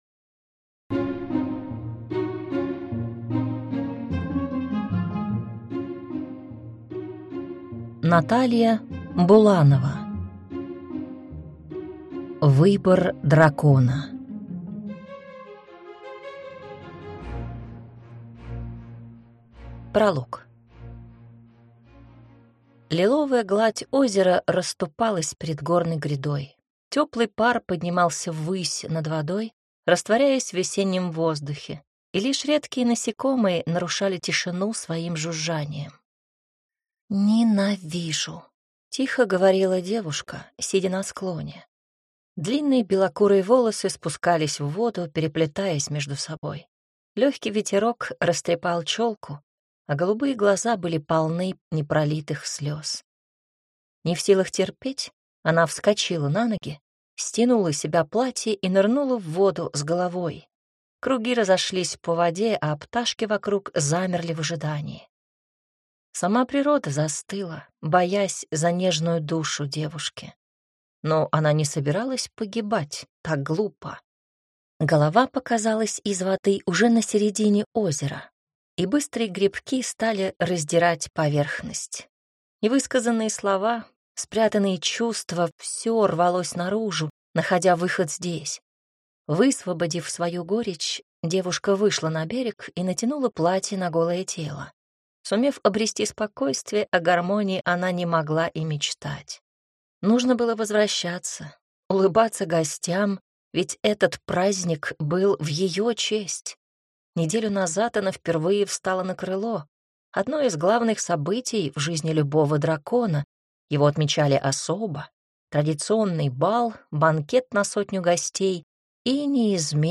Аудиокнига Выбор дракона | Библиотека аудиокниг